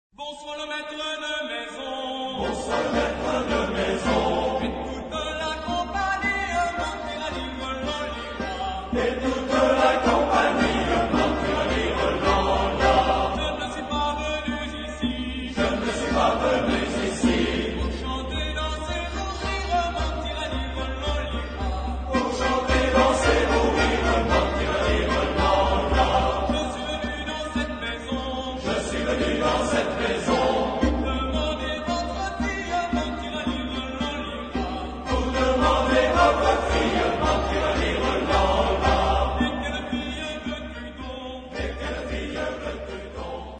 Genre-Stil-Form: Volkstümlich ; Kinder ; weltlich
Chorgattung: SAA ODER SSA ODER TBB ODER TTB  (3 Männerchor ODER Kinderchor ODER Frauenchor Stimmen )
Solisten: Ténor (1)  (1 Solist(en))
Tonart(en): As-Dur